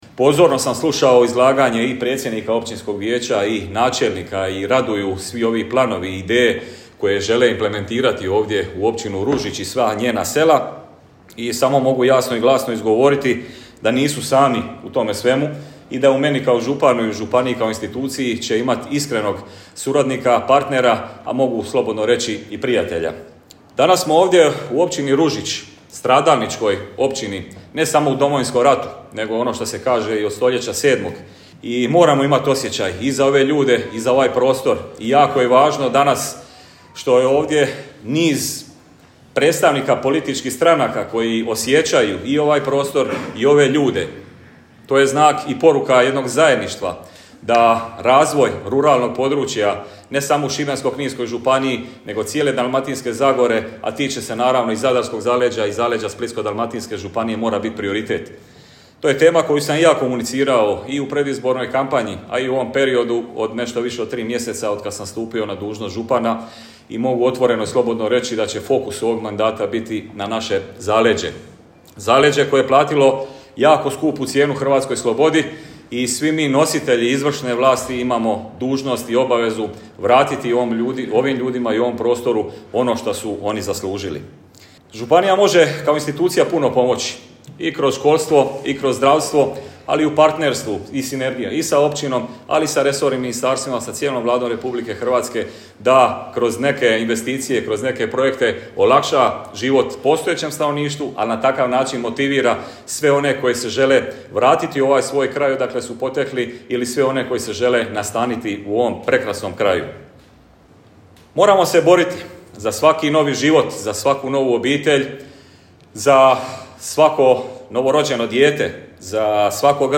Svečano proslavljen Dan Općine Ružić i blagdan Male Gospe - Radio Drniš
U svom pozdravnom govoru, župan Paško Rakić istaknuo je važnost zajedništva, sinergije i suradnje svih razina vlasti za dobrobit Općine Ružić te svim njezinih stanovnika: